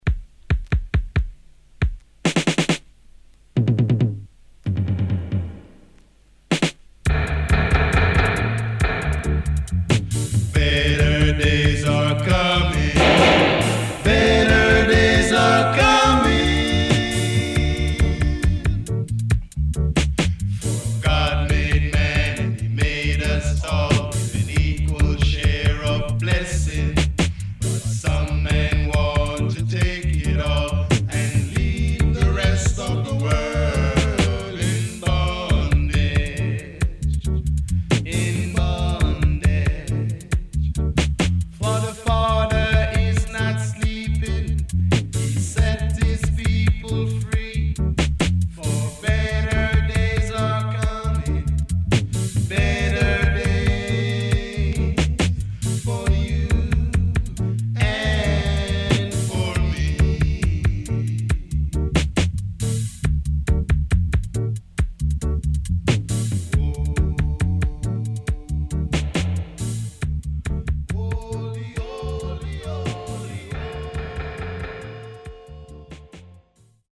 HOME > REISSUE [REGGAE / ROOTS]
激渋いUK Rootsの85年未発表音源。